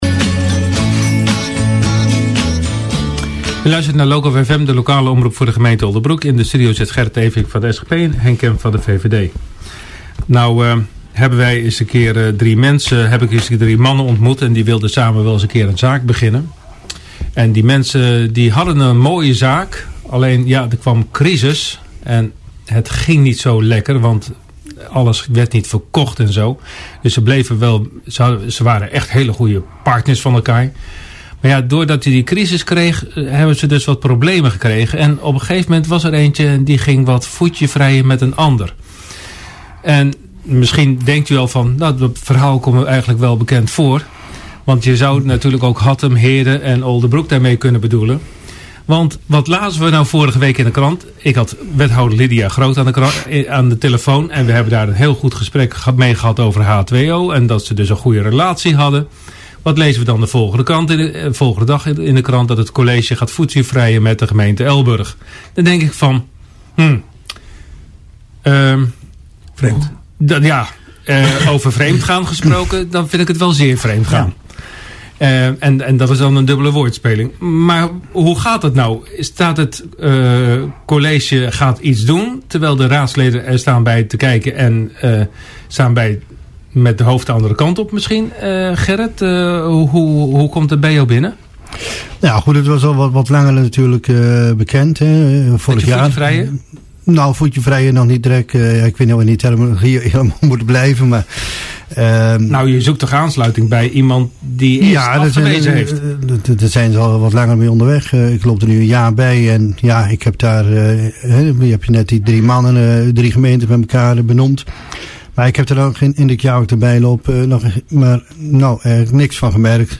Re: Gemeenten onderzoeken verdergaande samenwerking Citeer Bericht door Redactie » za feb 28, 2015 10:16 Dit onderwerp werd ook besproken tijdens het Loco radioprogramma Rondje Politiek op woensdag 25 februari. Namens de politiek was hier aanwezig de SGP en de VVD.